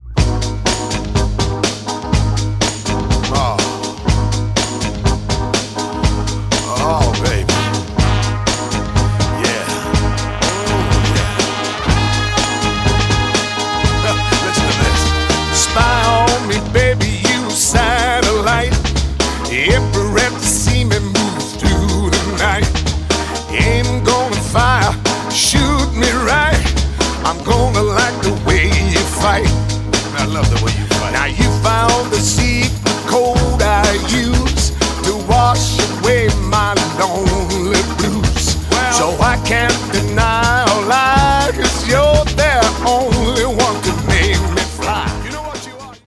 поп
веселые
Британский эстрадный певец